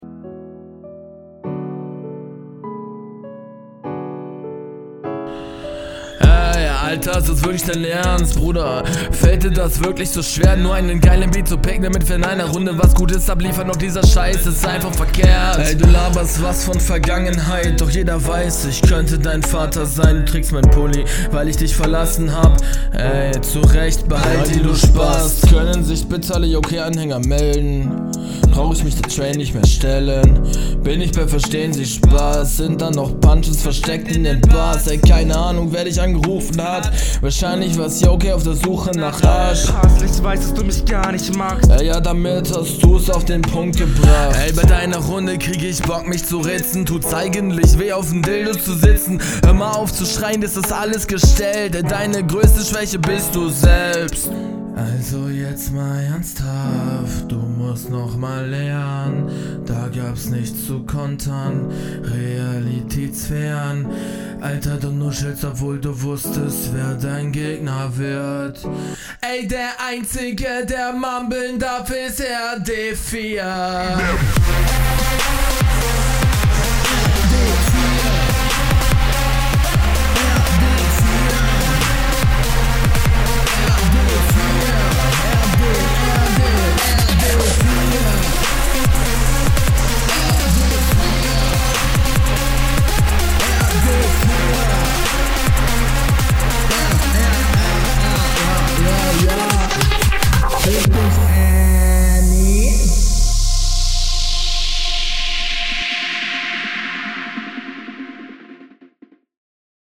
Deine Stimme kommt mega fett und klingt sehr ausgereift. 10/10 Delivery.